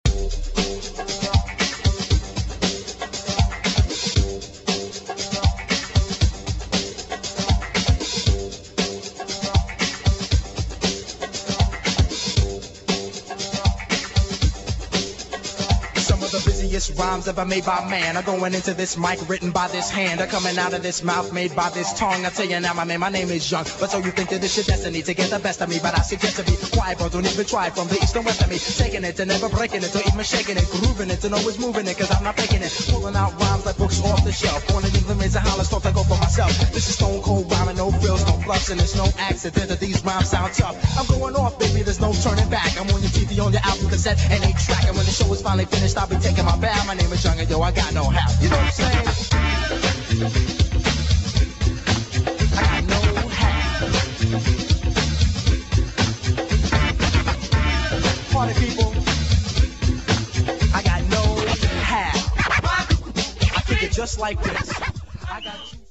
1. HOUSE | DISCO